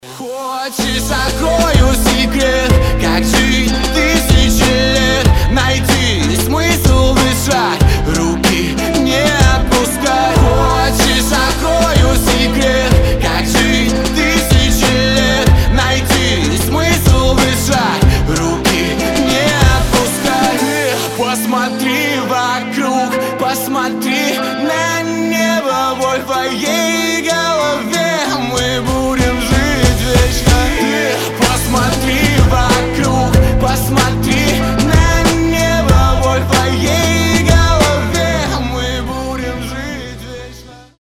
• Качество: 320, Stereo
Хип-хоп
грустные
русский рэп
мотивирующие
лиричные